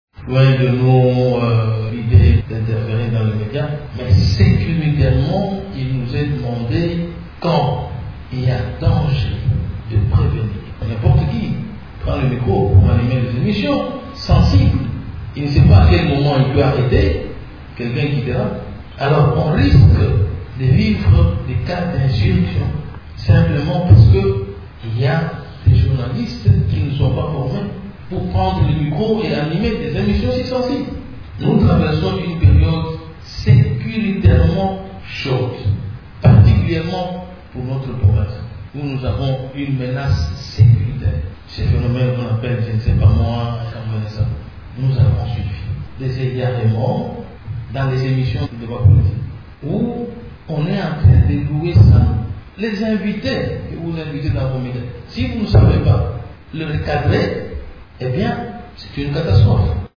Au cours d’une conférence de presse jeudi 15 décembre à Mbuji-Mayi, le gouverneur du Kasaï-Oriental, Alphonse Ngoyi Kasanji, a annoncé la mesure interdisant la diffusion des émissions de débats politiques dans les médias locaux.